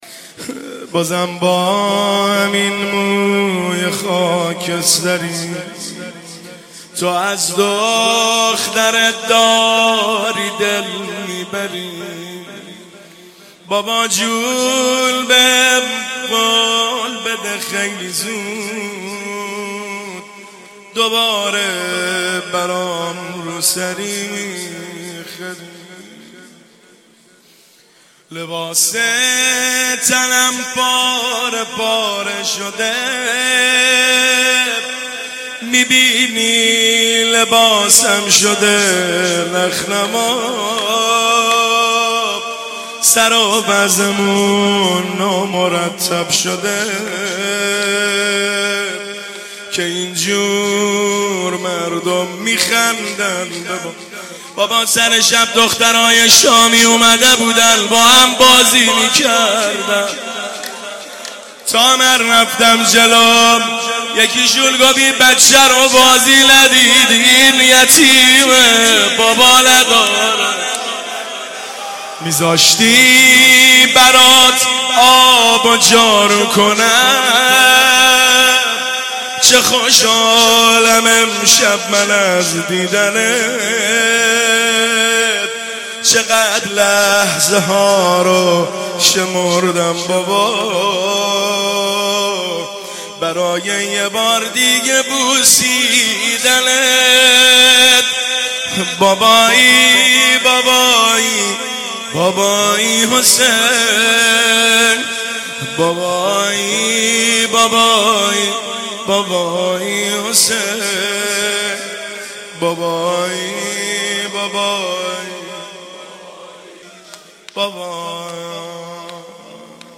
شب سوم محرم - به نام نامیِ حضرت رقیه(س)
روضه